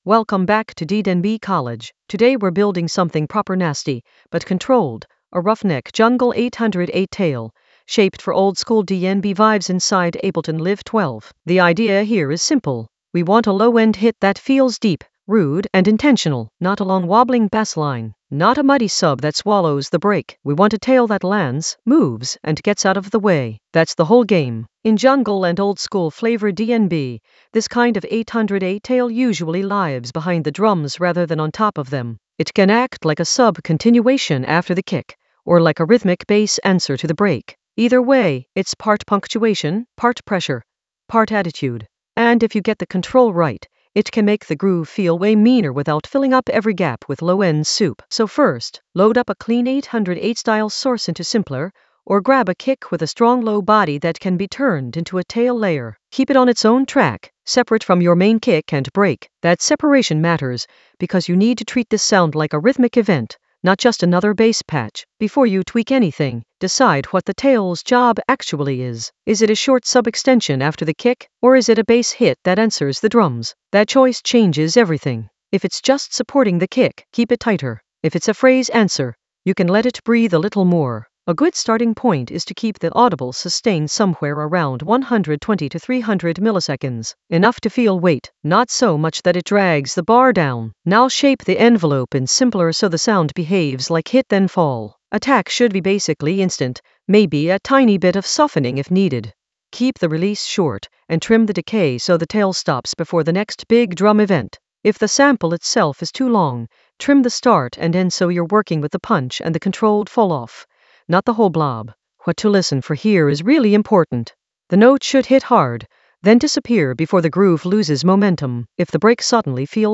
An AI-generated intermediate Ableton lesson focused on Ruffneck a jungle 808 tail: control and arrange in Ableton Live 12 for jungle oldskool DnB vibes in the Groove area of drum and bass production.
Narrated lesson audio
The voice track includes the tutorial plus extra teacher commentary.